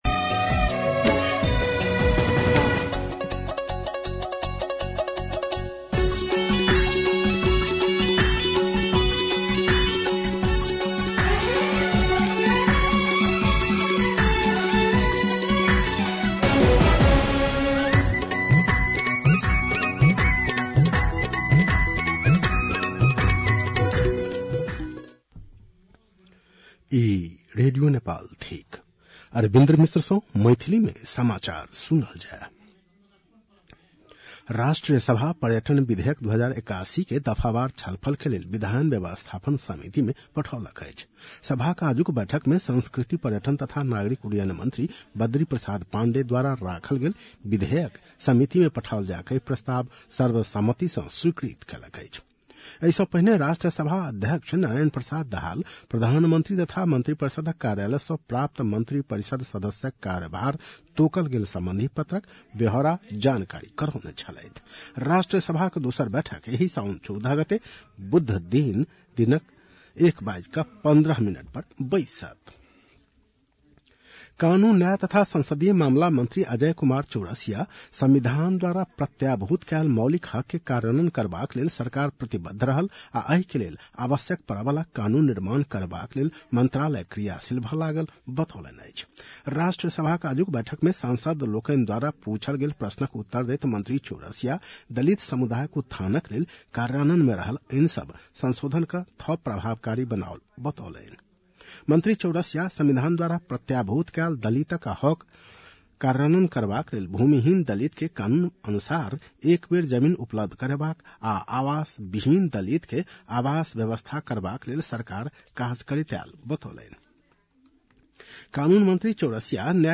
मैथिली भाषामा समाचार : ११ साउन , २०८२
6.-pm-maithali-news-1-3.mp3